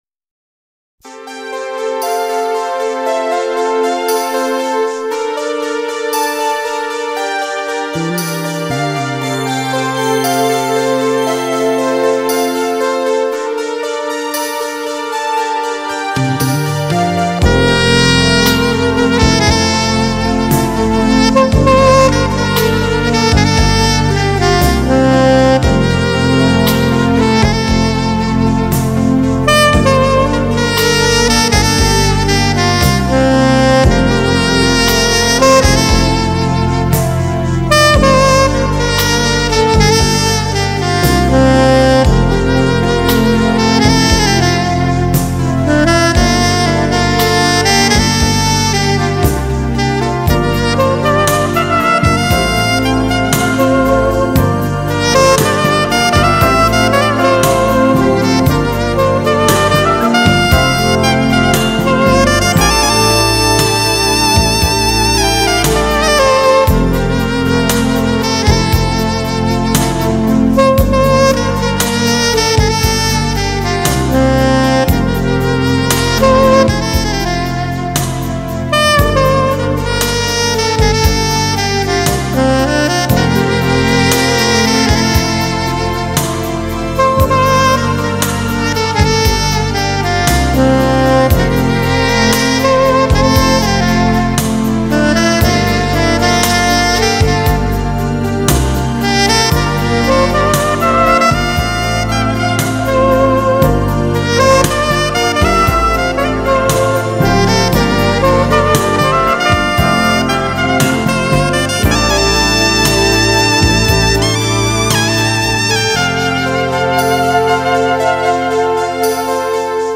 경음악